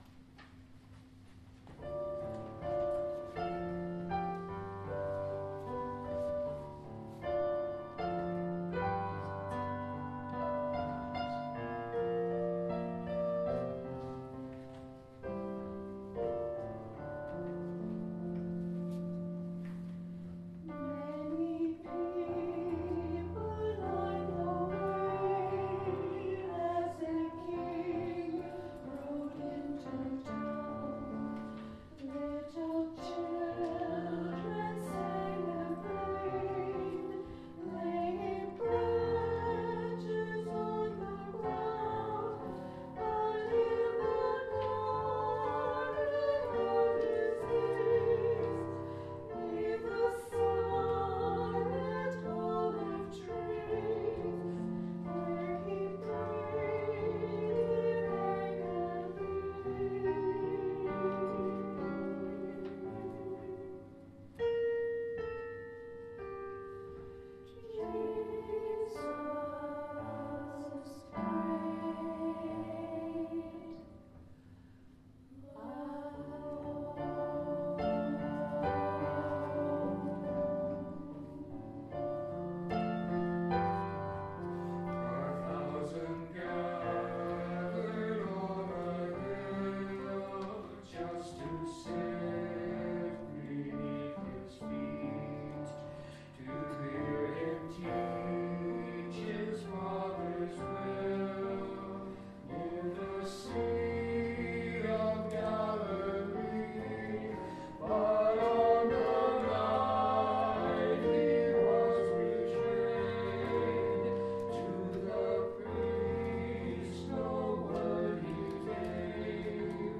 BUC Worship Service, Good Friday – April 3, 2026 – balzacunitedchurch new
Choir Link
Choir Special Jesus Died Alone arr. J. Raney
BUC-Choir_Apr3_2026_Jesus-Died-Alone.mp3